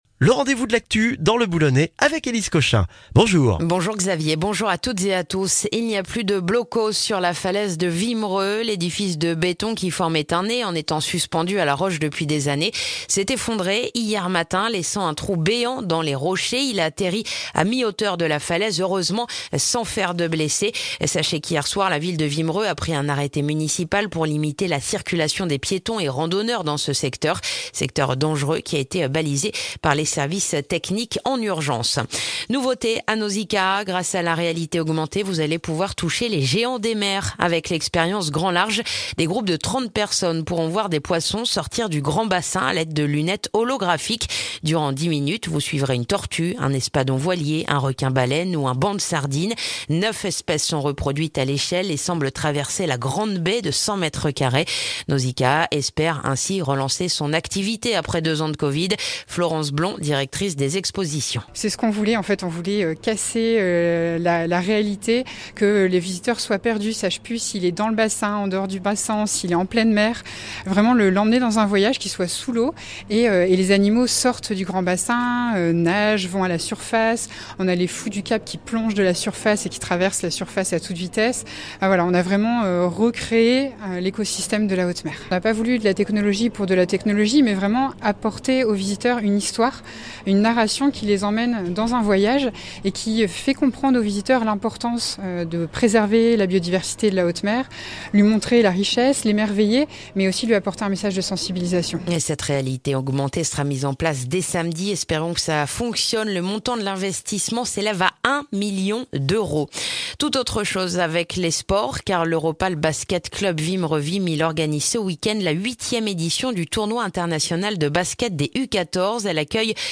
Le journal du vendredi 3 juin dans le boulonnais